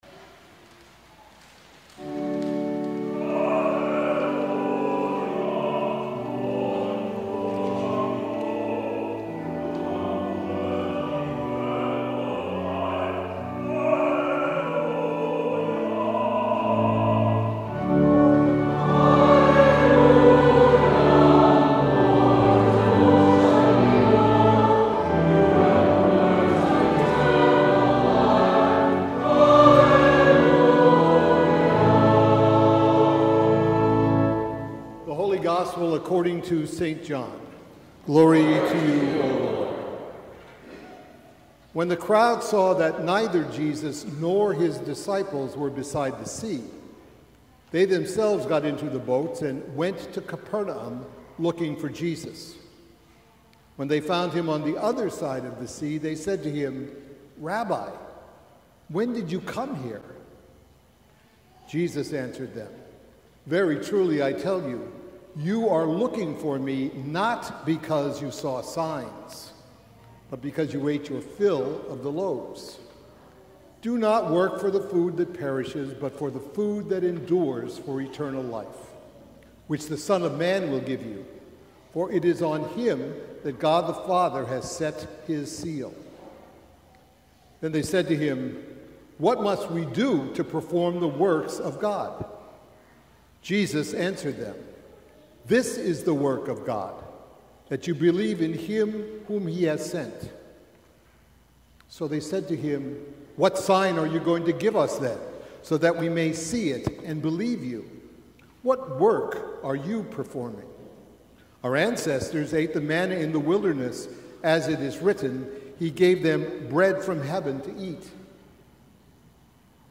Sermon from the Eleventh Sunday After Pentecost